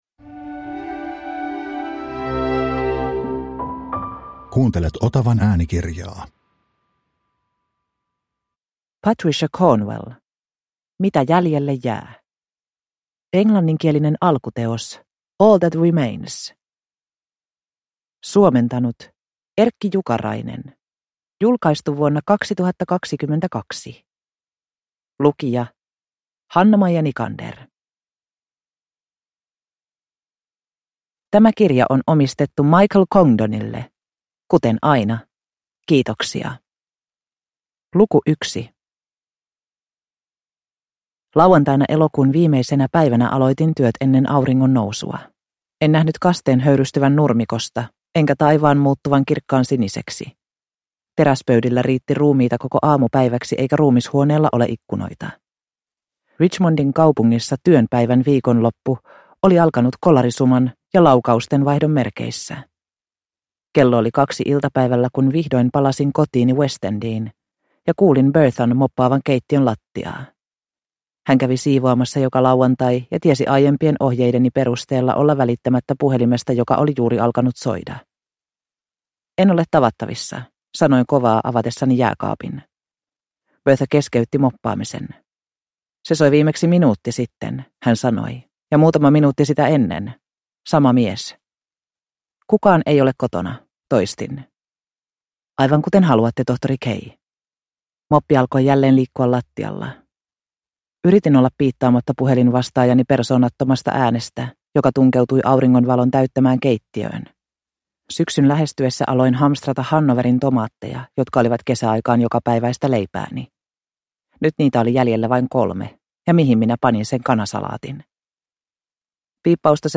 Mitä jäljelle jää – Ljudbok – Laddas ner